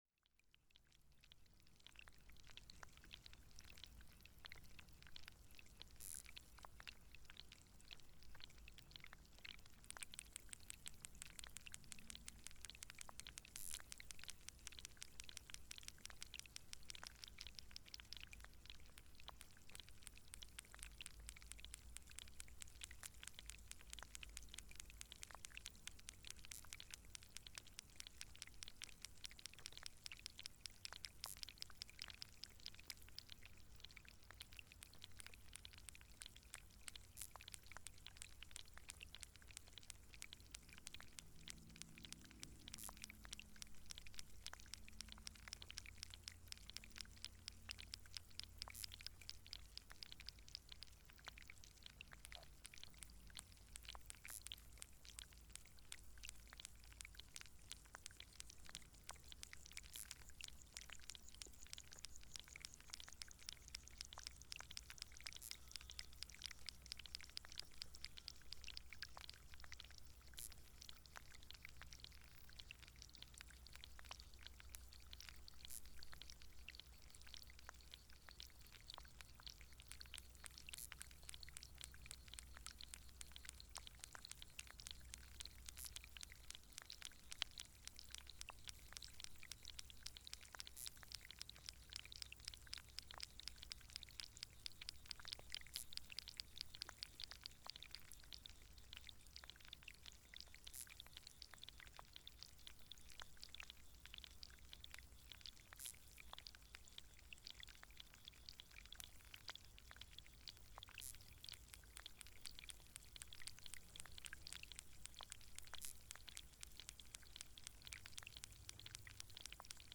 Agabus beetles in the pond
Recorder: Sound devices MixPre6 Hydrophones:. Benthowave BII-7121 Pix: Canon EOS-R
There was no exception to this in the nature reserve in Flói, southwest Iceland 17th July 2022.
So it was the perfect time to dip the Hydrophones in the next pond while waiting for the coffee. The pond was full of life, although it could not be seen or heard on the surface.
Some of these sounds may also be methane gas being released from the bottom of the pond. The soundscape in the pond was similar to birdlife. The chorus from the biosphere in the pond came in waves. Sometimes the sounds were few and quiet, but then there came a time when the whole biosphere in the pond needed to be heard.
The recording was recorded in 24bit/48Khz, but a lot of these sounds seem to be able to go well above 24Khz.